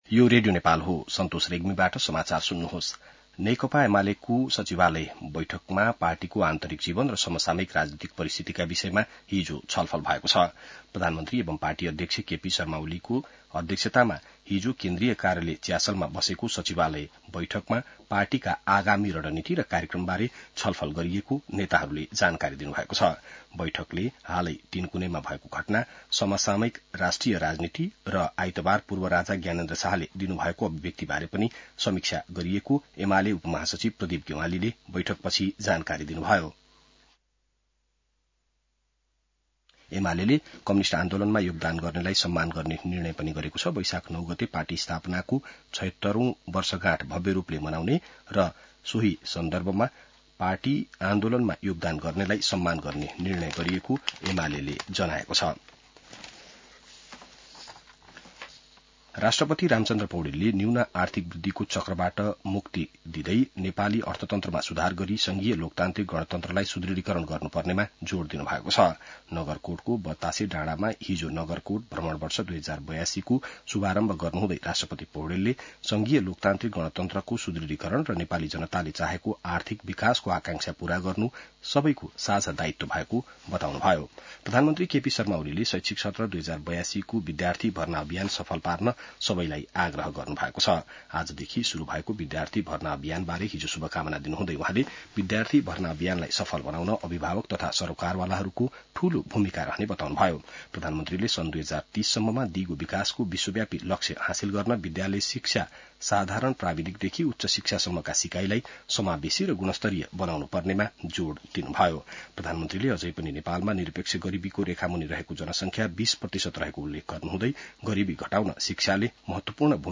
बिहान ६ बजेको नेपाली समाचार : २ वैशाख , २०८२